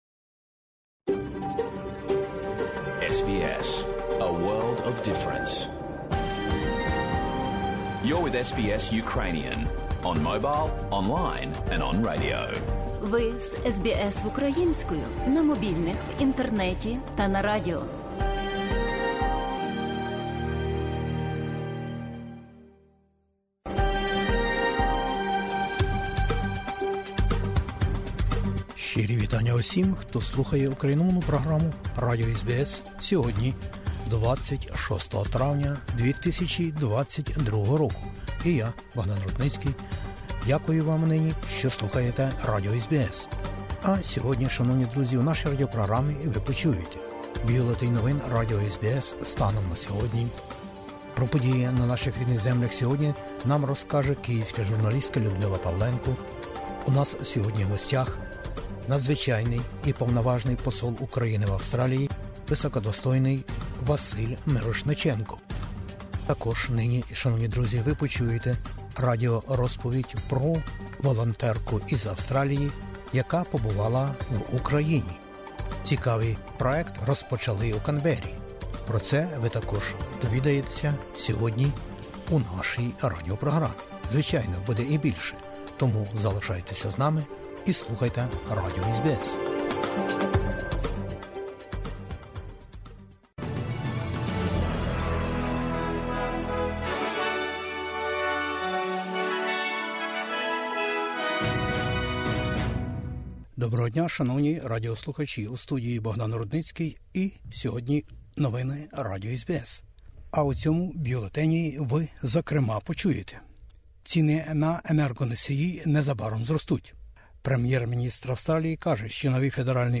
Бюлетень СБС новин українською. Після виборів - питання липневого подорожчання енерґії для споживачів. Прем'єр-міністр каже, що особливо дбатиме про економіку напередодні оголошення повного складу Федерального уряду.